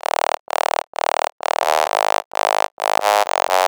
Broken Oscillator Eb 130.wav